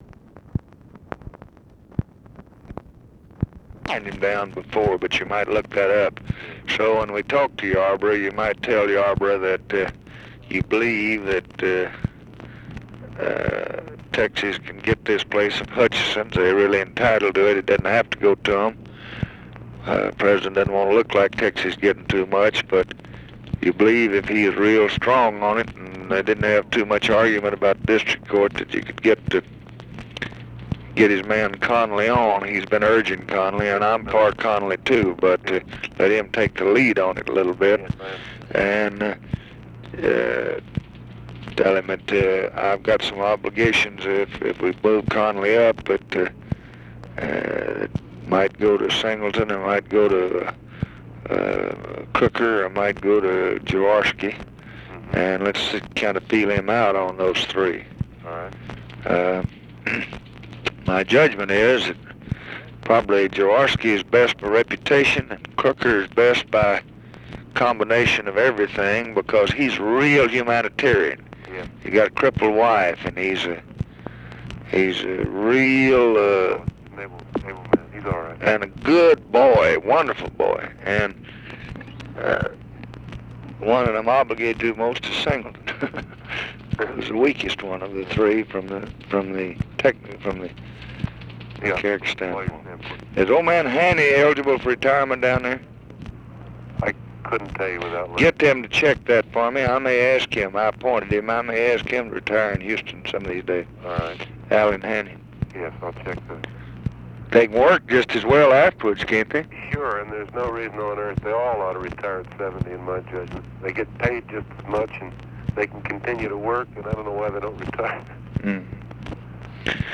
Conversation with NICHOLAS KATZENBACH, November 12, 1964
Secret White House Tapes